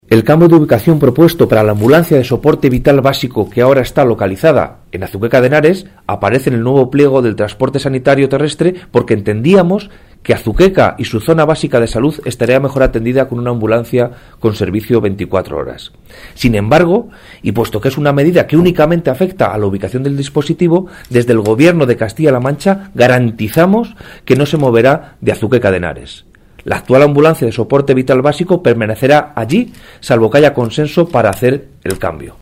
delegado Guadalajara ambulancias 01
delegado_guadalajara_ambulancias_01.mp3